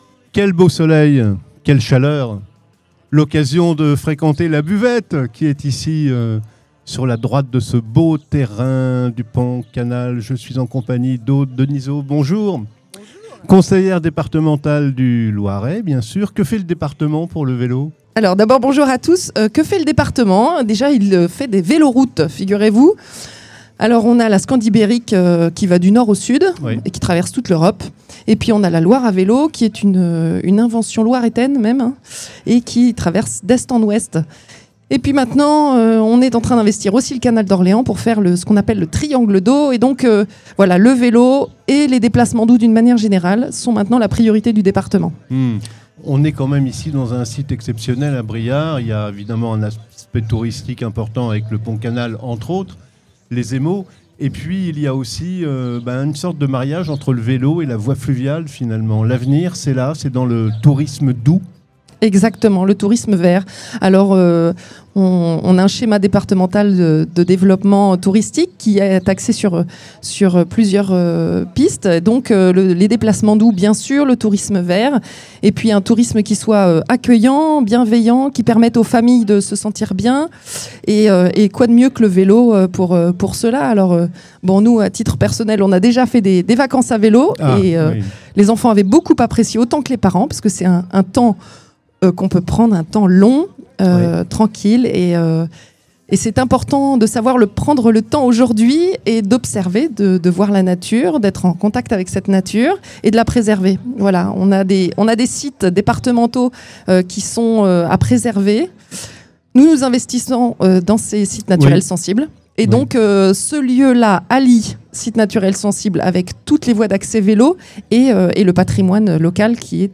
Interview - Aude Denizot - Conseillère départementale
À l’occasion du Grand Répar Vélo des Turbines, Studio 45 vous propose une série d’interviews réalisées en direct du Pont Canal de Briare.